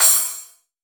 CRASH_R.WAV